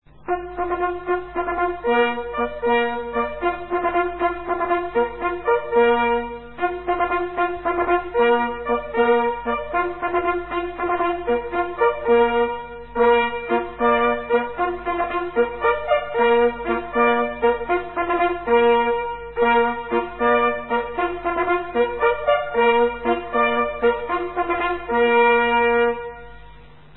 Jagdhornbläser
Wenn auch die Jagdhörner in der Zahl der zur Verfügung stehenden Töne begrenzt sind, so vermitteln sie doch mit ihren einfachen Melodienfolgen beim Zuhörer einen unvergleichlichen naturverbundenen Eindruck.